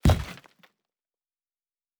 Fantasy Interface Sounds
Stone 08.wav